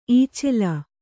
♪ īcela